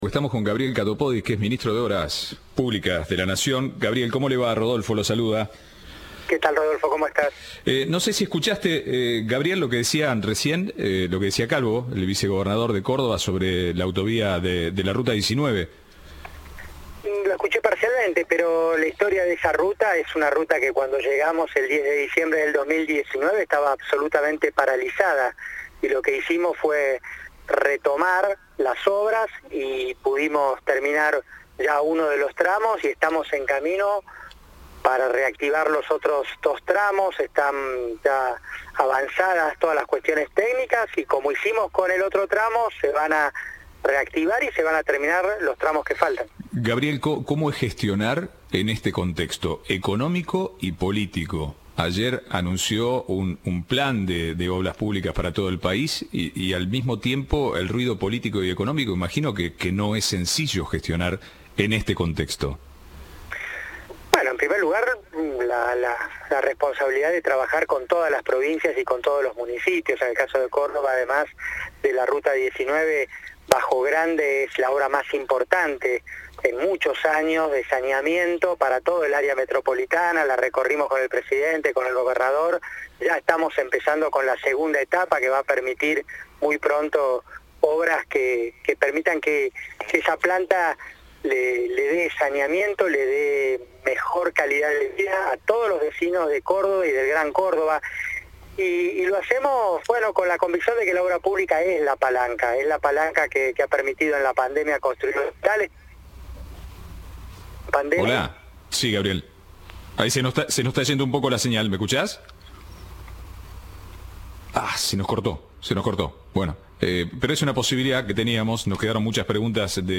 Entrevista de Rodolfo Barili.